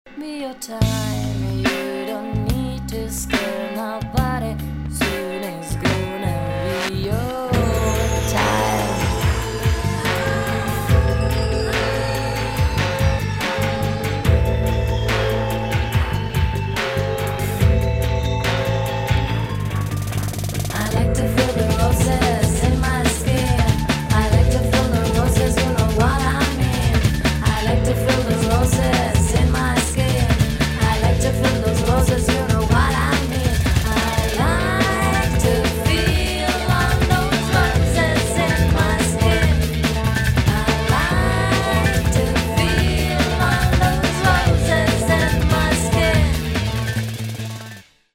[ DOWNBEAT / BREAKBEAT ]
ディープ＆メロウなダウンテンポ・ブレイクビーツ・アルバム！！